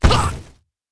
khanat-sounds-sources/sound_library/voices/death/loud/orni_die3.wav at f42778c8e2eadc6cdd107af5da90a2cc54fada4c
orni_die3.wav